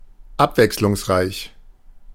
Ääntäminen
Ääntäminen US Haettu sana löytyi näillä lähdekielillä: englanti Käännös Ääninäyte Adjektiivit 1. abwechslungsreich 2. mannigfaltig Varied on sanan vary partisiipin perfekti.